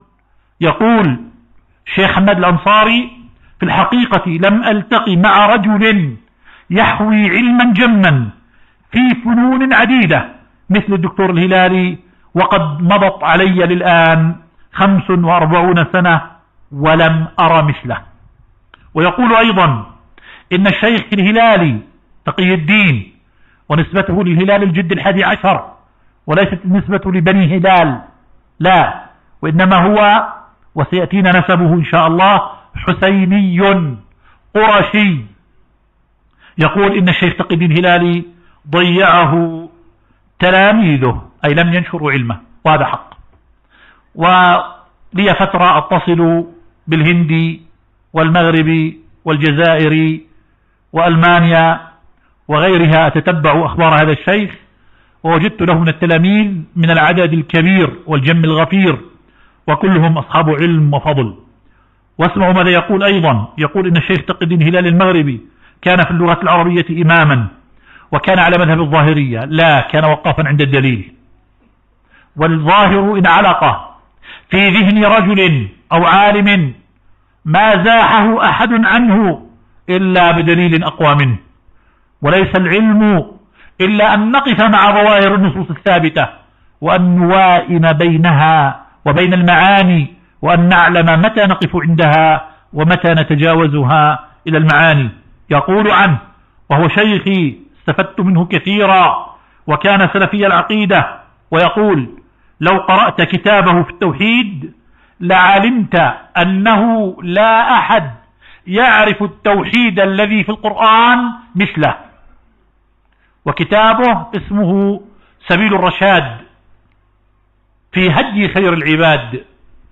محاضرة “محمد تقي الدين الهلالي